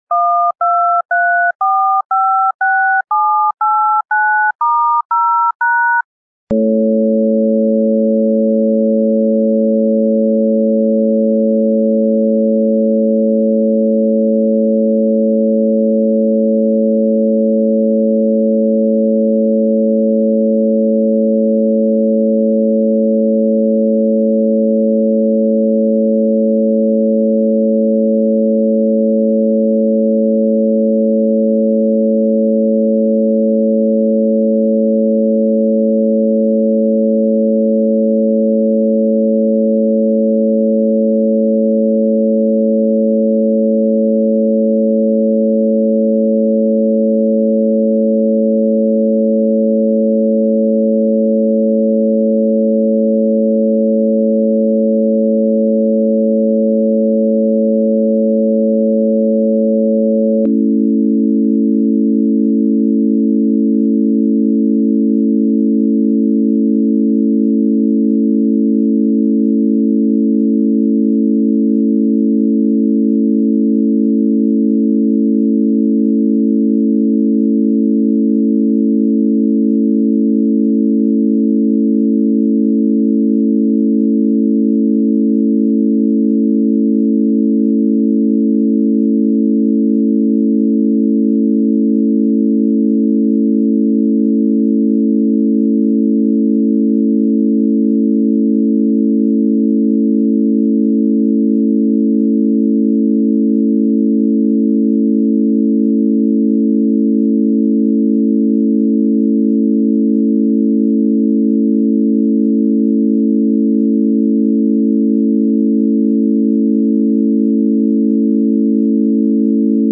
32kbps CBR MP3 (generated from 22kHz 16bit Mono)
Created attachment 4045 [details] 32kbps CBR MP3 (generated from 22kHz 16bit Mono) Demonstrates playback problem on Boom and SB3. Starts with sequence of 12 dial tones (6 secs) followed by two minutes of pleasant hum, followed by another 6 seconds of dial tones.